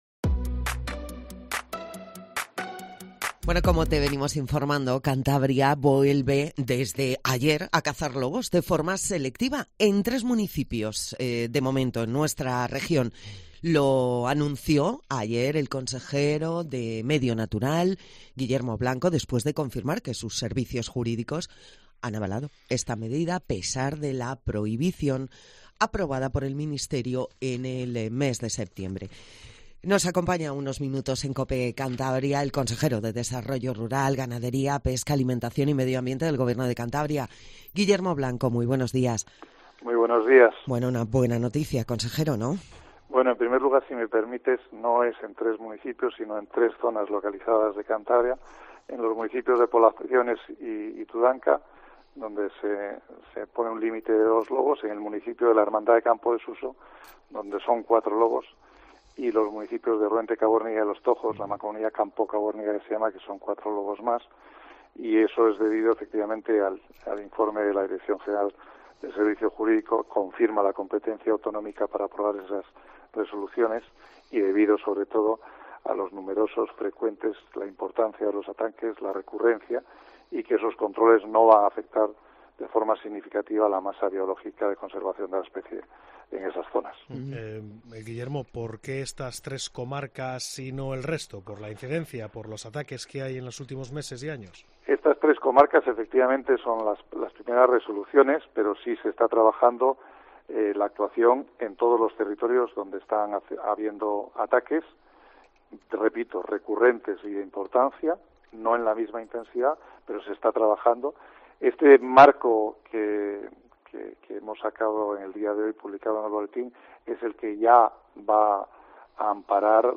Escucha al consejero Guillermo Blanco en Cope, sobre la resolución que permitirá el abatimiento de 10 lobos